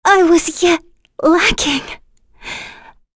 Laias-Vox_Dead_b.wav